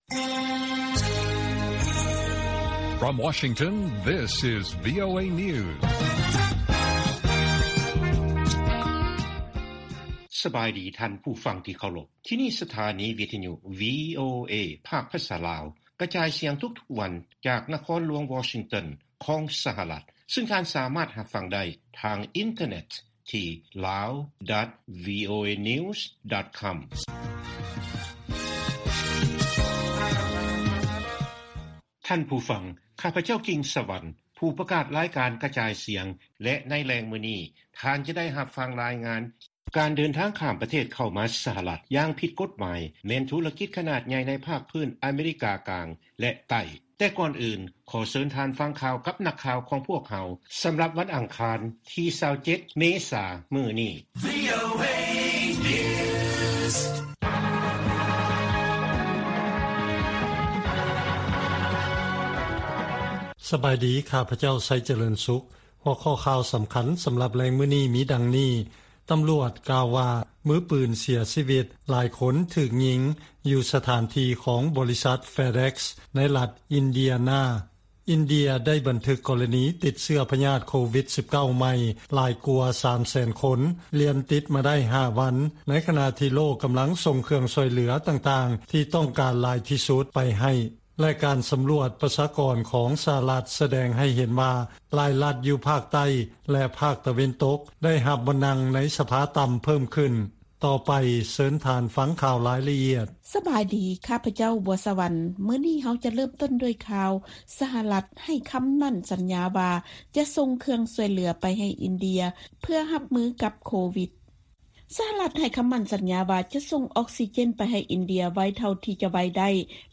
ວີໂອເອພາກພາສາລາວ ກະຈາຍສຽງທຸກໆວັນ. ຫົວຂໍ້ຂ່າວສໍາຄັນໃນມື້ນີ້ມີ: 1)ສຫລ ຈັດເອົາລາວ ເຂົ້າໃນບັນຊີແດງ ຂອງການເດີນທາງ ທີ່ຖືວ່າມີຄວາມສ່ຽງສຸດຂີດ ໃນການຕິດ ພະຍາດ ໂຄວິດ.